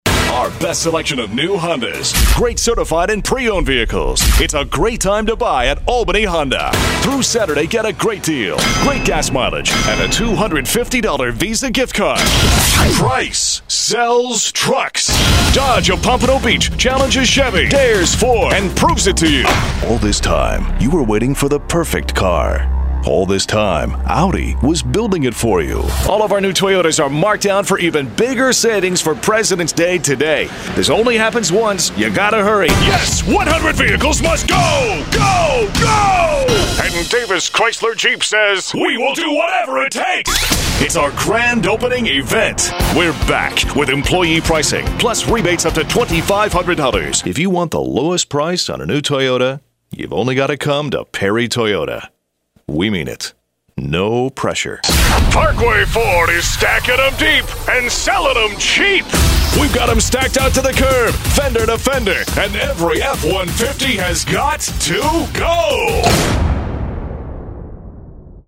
Commercial
Automotive